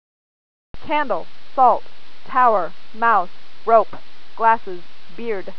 Finally, you will be read a list of 7 everyday words.